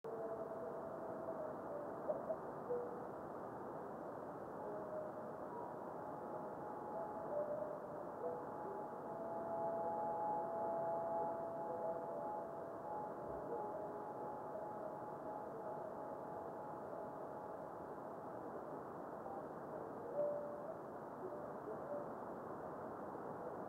Weak radio reflection but evident.